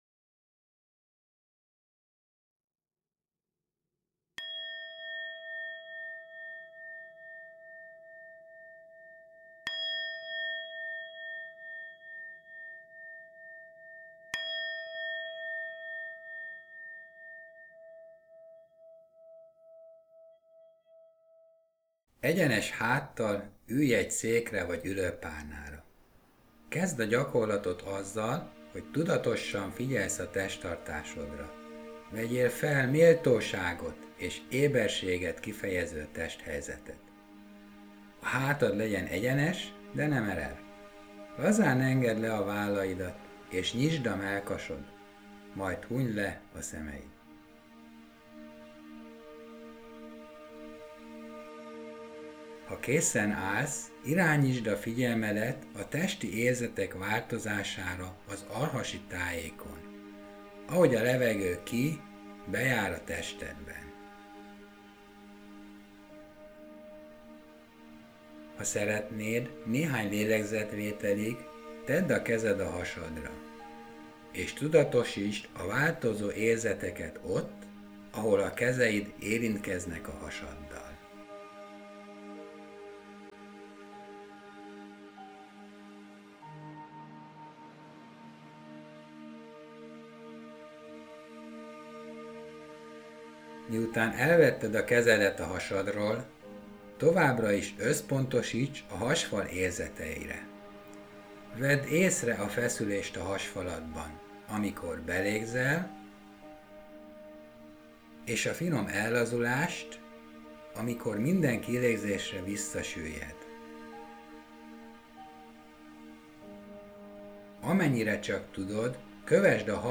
Teremtő meditáció